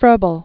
(frœbəl), Friedrich Wilhelm August 1782-1852.